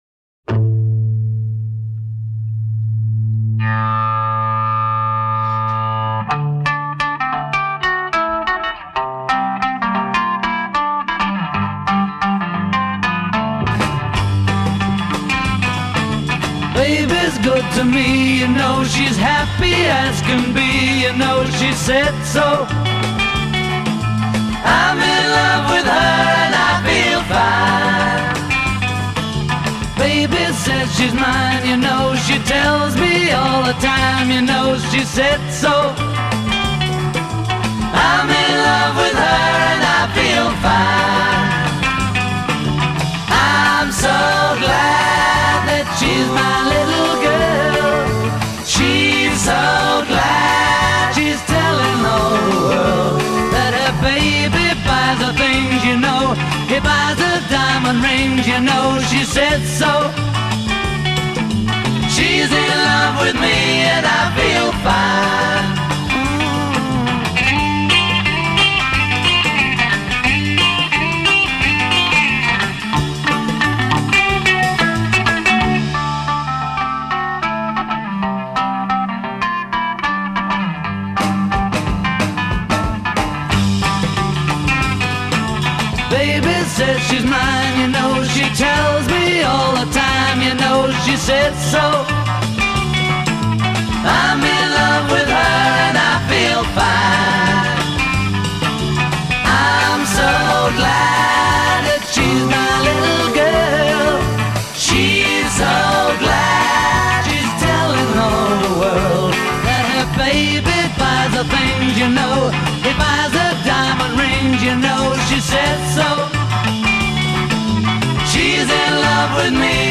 voice & rhythm guitar
voice & bass guitar
lead guitar
drums
It's a coloured acoustic.
intro 0:00 8 guitar riff
A verse 0: 6 solo vocal over ensemble a
refrain : 4 vocal harmony with soloist on hook b
(A) verse : 6 guitar solo
coda   3+ as in refrain above; repeat and fade f